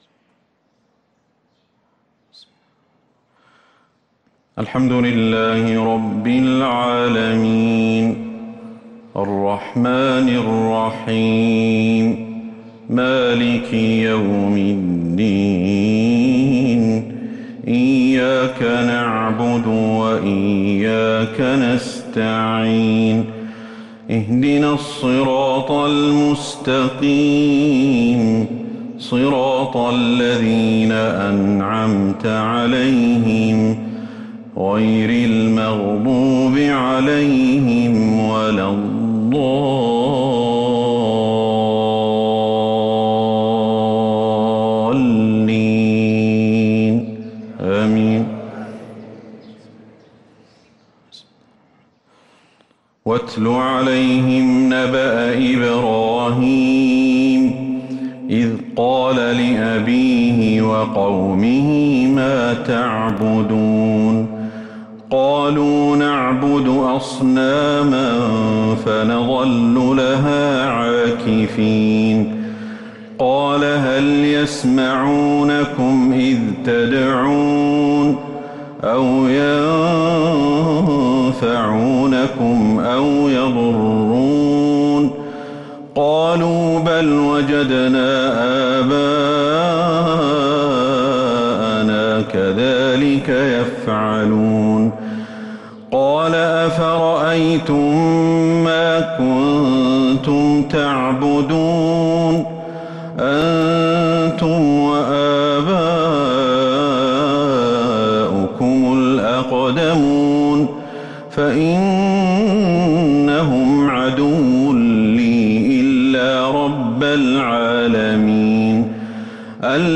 صلاة الفجر للقارئ أحمد الحذيفي 9 محرم 1443 هـ